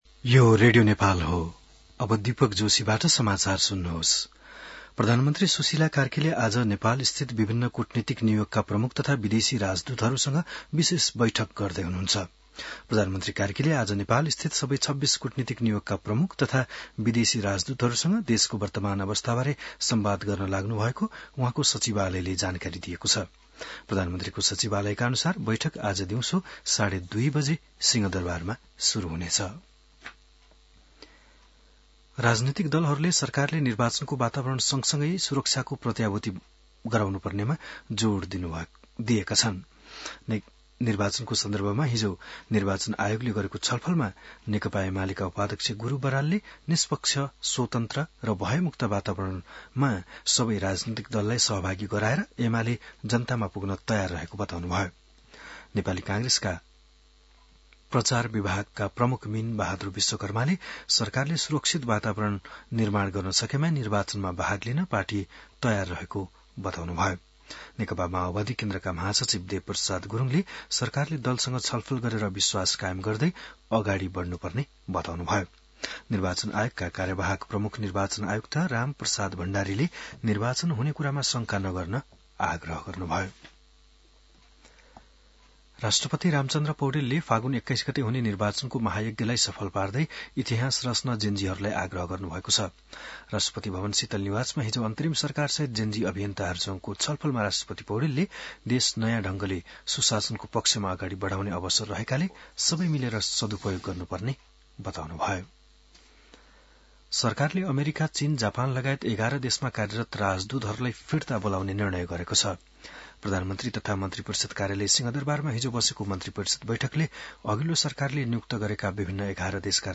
बिहान १० बजेको नेपाली समाचार : ३१ असोज , २०८२